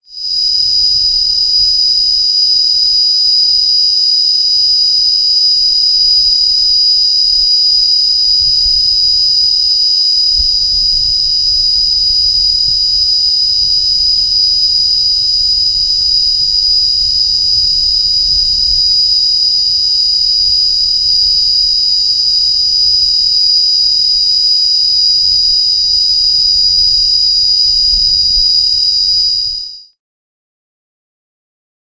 Cicadas emit species-specific tymbalizations (songs), which are available online as supporting material to this volume (111 downloadable audio tracks).
Cicada tymbalization downloads